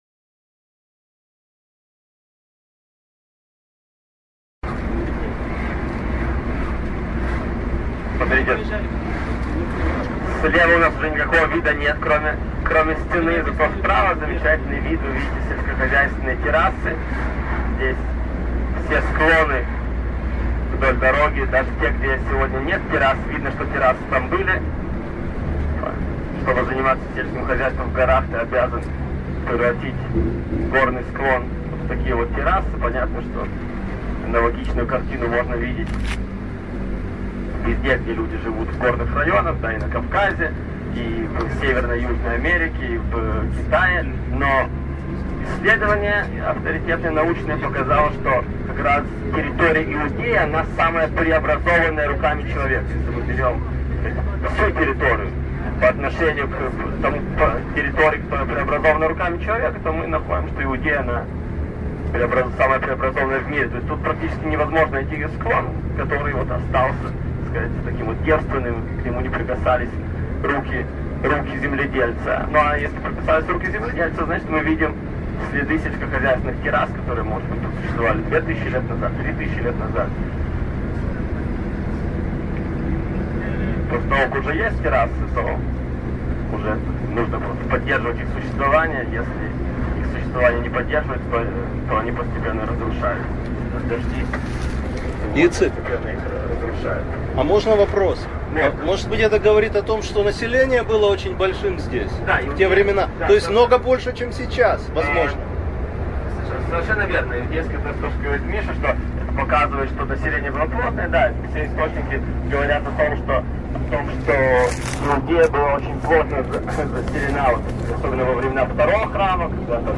Послушать экскурсовода: Откуда тоннели и мосты на новой хевронской дороге?